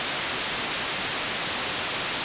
Spray.wav